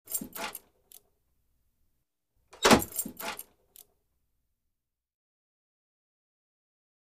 Creak, Mailbox | Sneak On The Lot
Metal Mailbox Open And Close With Keys Jingle And Creaks